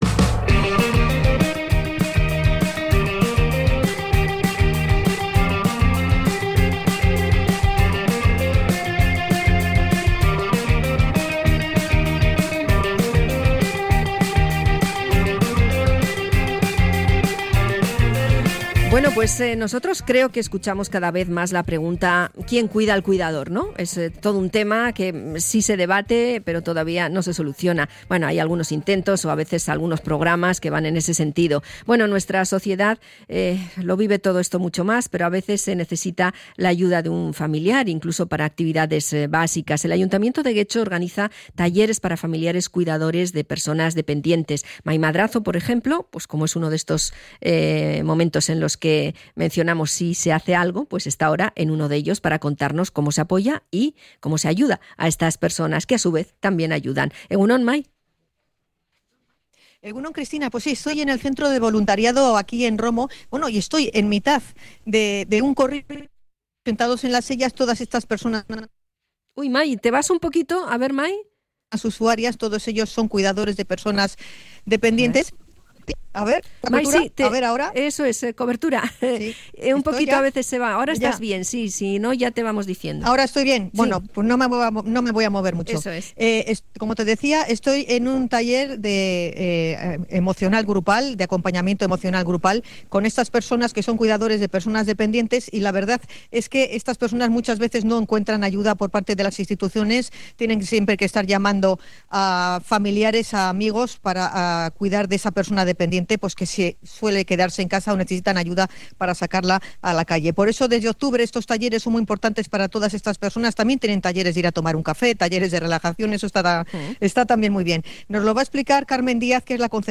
Hablamos con usuarios de los talleres para cuidadores en Getxo